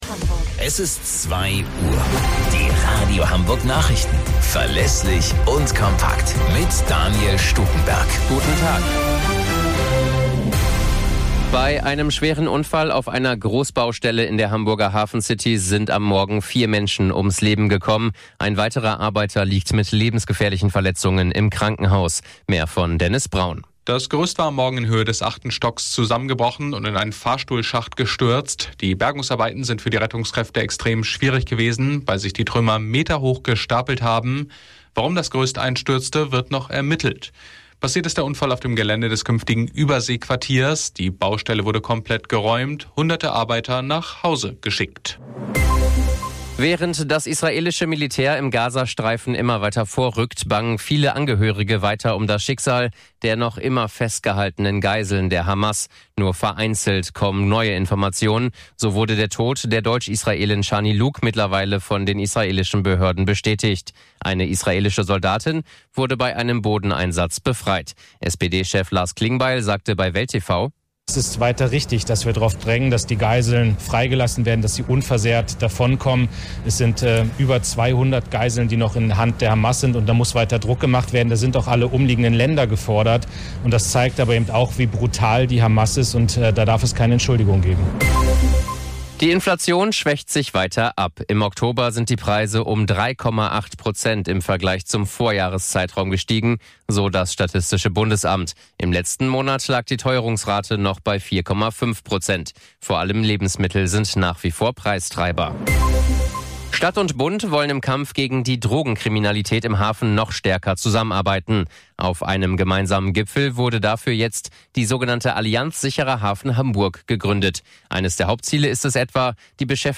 Radio Hamburg Nachrichten vom 31.10.2023 um 09 Uhr - 31.10.2023